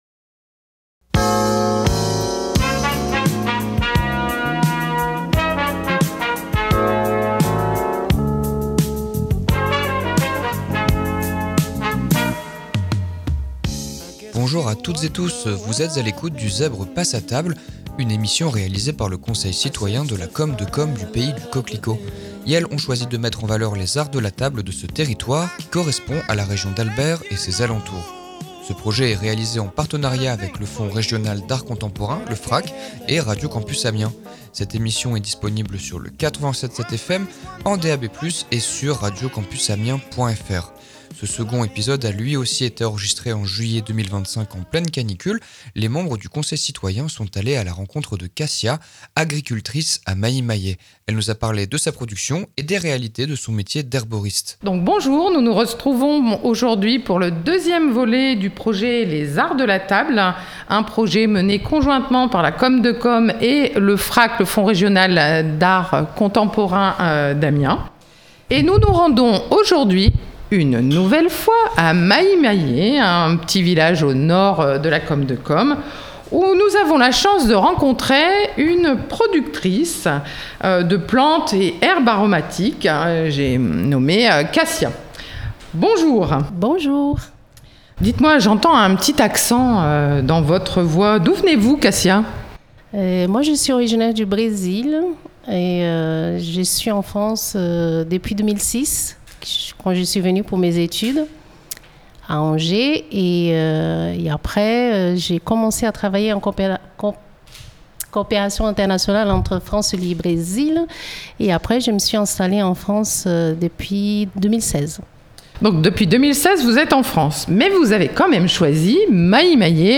Les membres du conseil citoyen d’Albert ont choisi de monter une émission de radio intitulée “Le Zèbre passe à table”. L’idée est de vous présenter le patrimoine des Arts de la Table du Pays du Coquelicot.